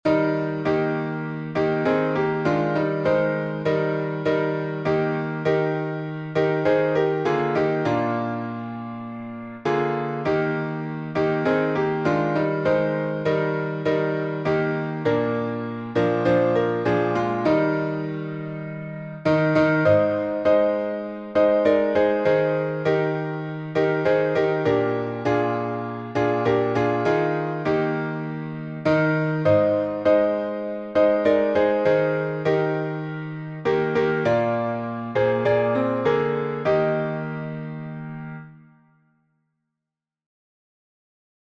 I Know Whom I Have Believed — five stanzas, E flat.